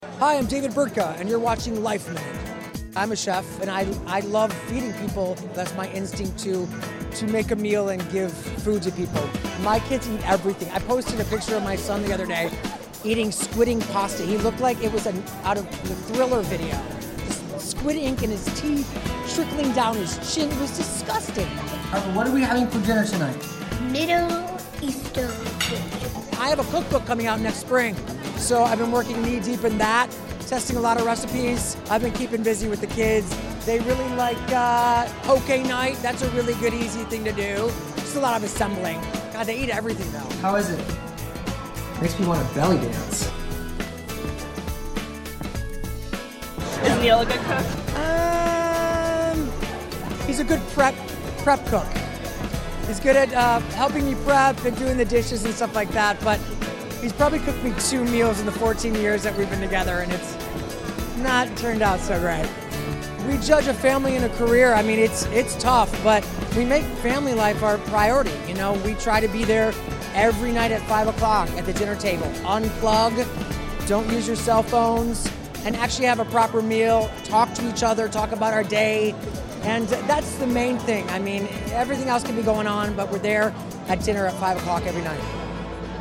David Burtka is one busy dad. We caught up with the chef/actor/author at the Food Bank for New York City’s 35th anniversary at the annual Can Do Awards Dinner at Cipriani Wall Street in New York City a few weeks ago.